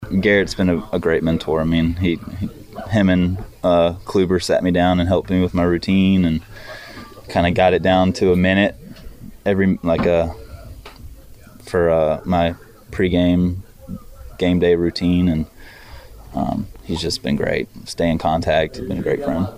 Cards Starting Pitcher Jordan Montgomery what did it mean for him to match up against former teammate and friend Gerrit Cole.